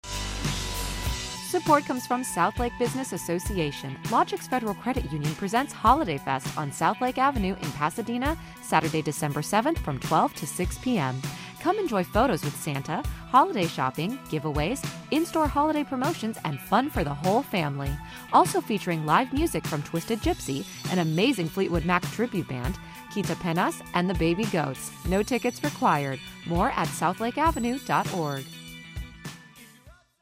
Click to listen to the South Lake Holidayfest 2024 KCRW Radio Spot!